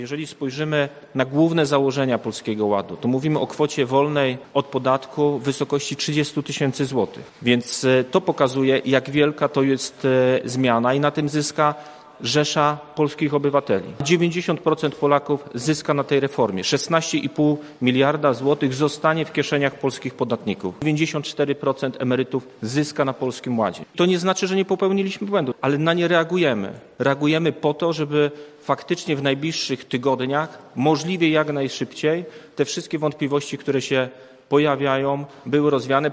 Wojewoda Zachodniopomorski – Zbigniew Bogucki podczas konferencji prasowej przekonywał do zalet rządowego programu Polski Ład, mówił również o popełnionych błędach.